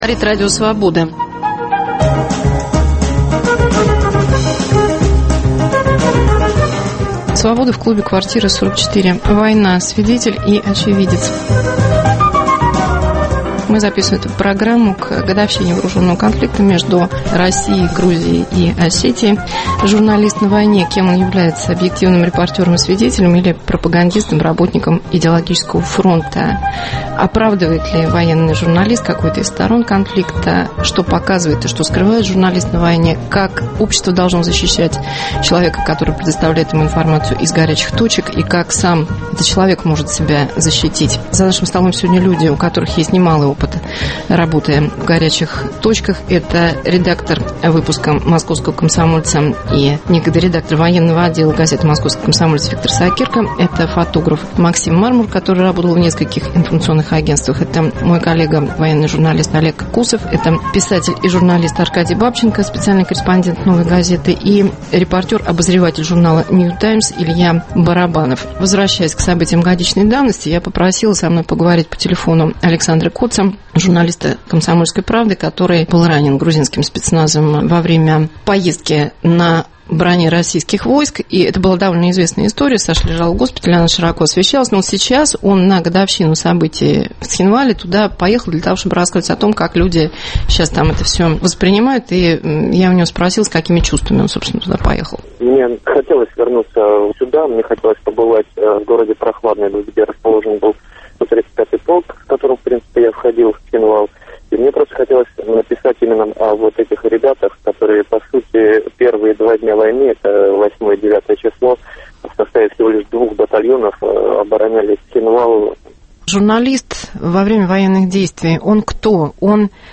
Свобода в клубе Квартира 44. Журналист на войне: свидетель и очевидец.